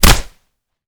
kick_hard_impact_07.wav